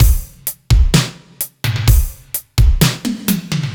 Index of /musicradar/french-house-chillout-samples/128bpm/Beats
FHC_BeatC_128-01.wav